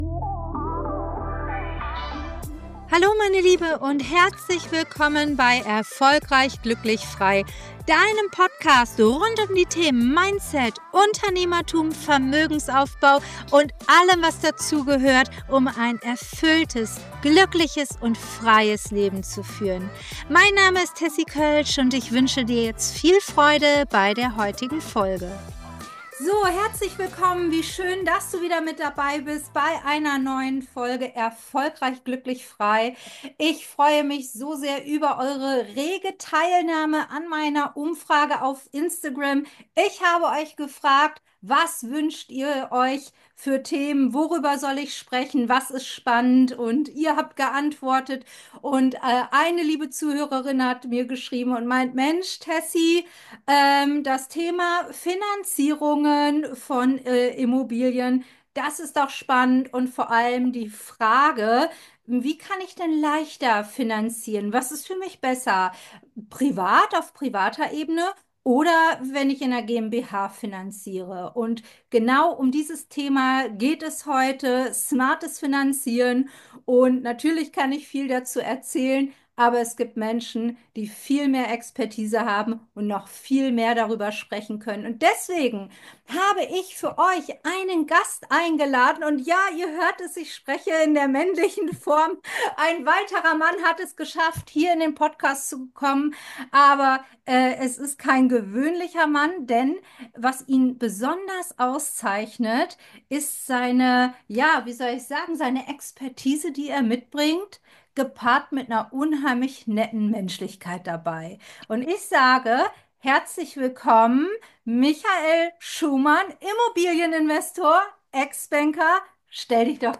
#23 Immobilien clever finanzieren: Privat oder GmbH? - Interview